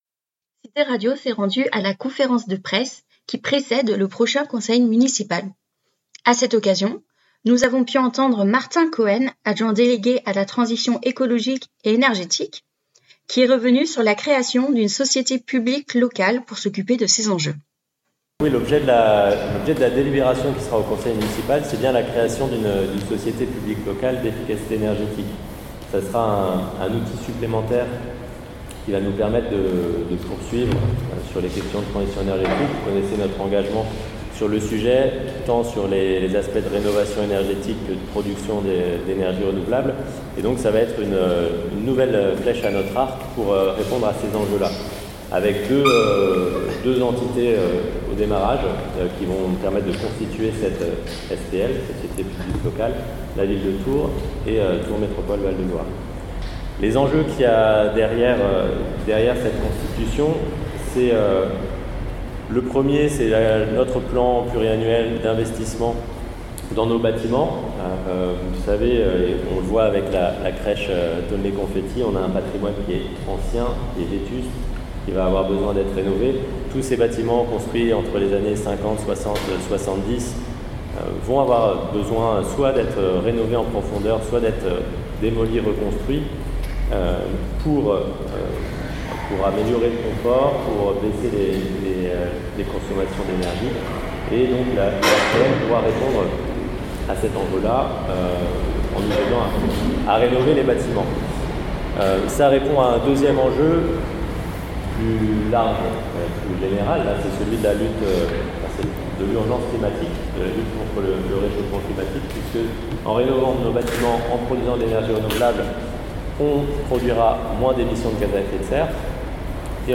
Ce vendredi 30 septembre s’est tenu dans la serre de l’Orangerie du jardin Botanique le pré-conseil municipal, avec comme principal thème la transition énergétique et la lutte contre l’inflation
Martin Cohen, conseiller municipal délégué à la transition énergétique
Martin-Cohen-conseil-municipal-de-Tours0.mp3